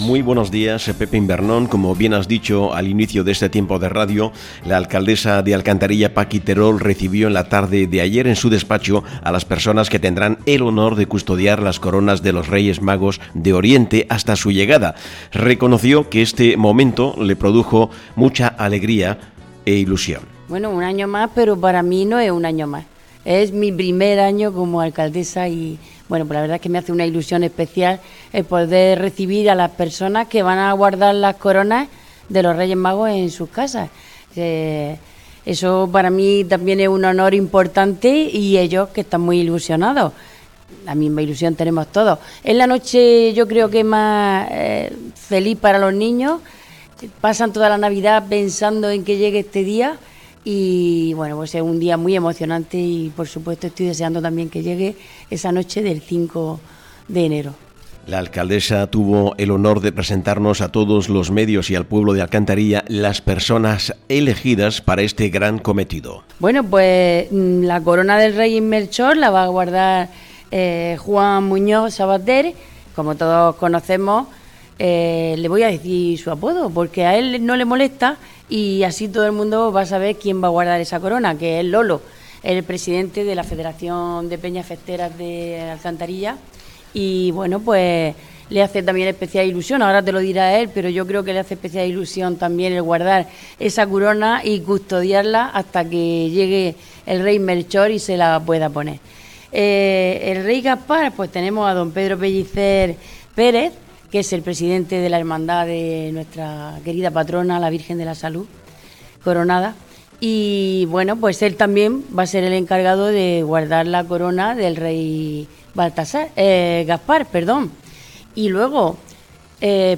Escucha el audio de la noticia con las voces de sus protagonistas.